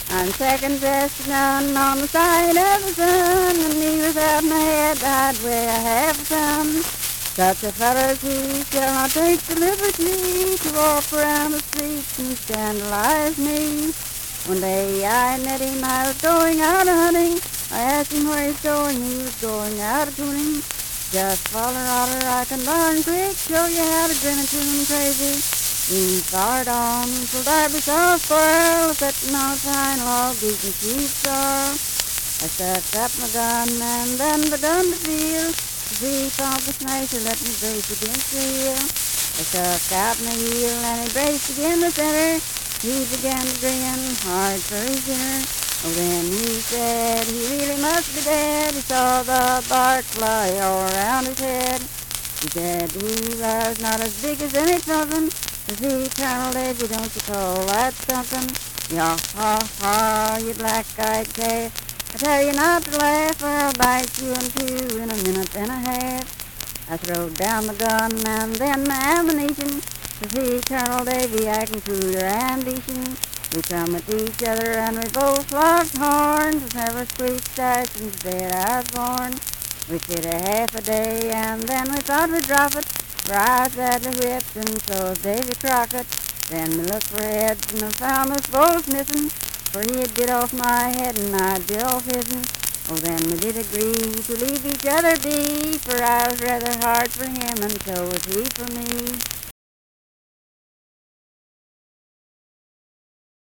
Unaccompanied vocal music
Performed in Daybrook, Monongalia County, WV.
Voice (sung)